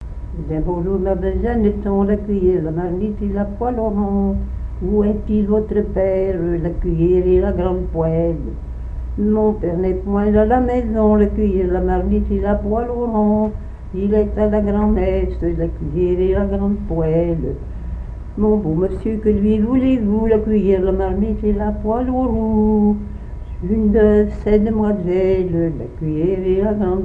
Genre strophique
répertoire de chansons
Pièce musicale inédite